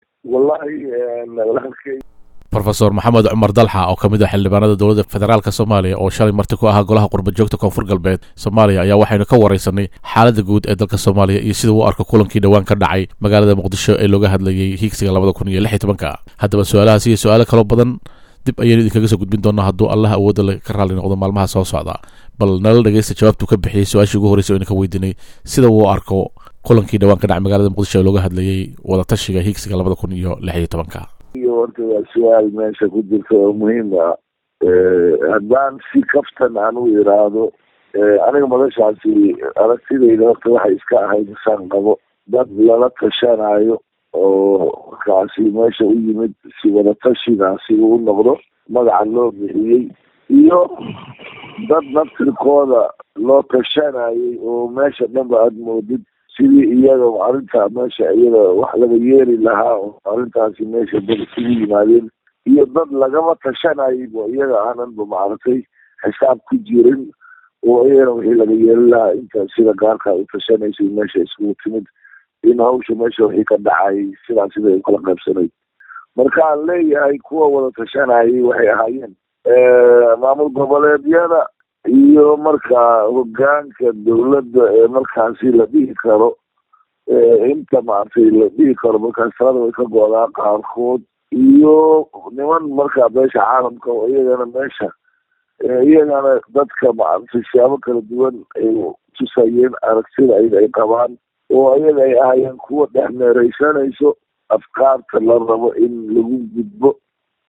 dalxaProf maxamed cumar dalxa oo ka mid ah xildhibaanada dowlada federaalka somaliya oo shaley marti ku ahaa golaha qurbo joogta koonfur galbeed ayaa waxaynu ka wareysaney xaalada guud ee dalka somaliya iyo siduu u arko kulankii wadatashiga ee dhawaan ka dhacay magalada muqdisho ee hiigsiga 2016.